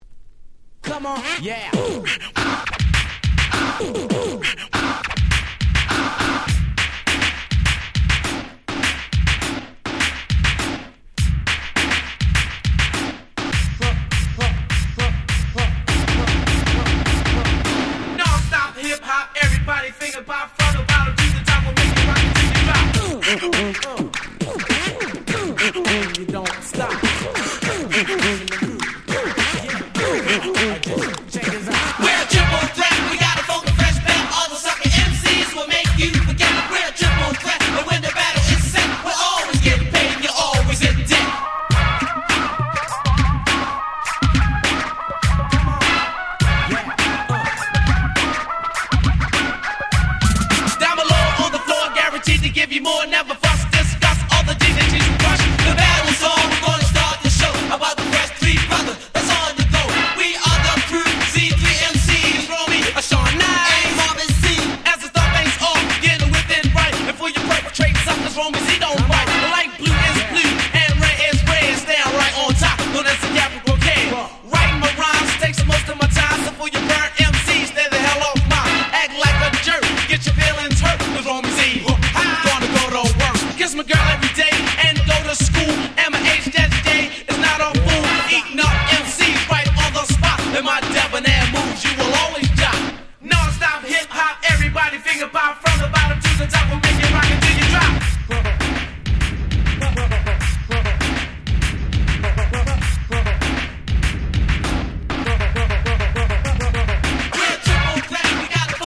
85' Super Hip Hop Classics !!
バトブレネタとしてもお馴染みの最強Old School !!
コスリネタ オールドスクール 80's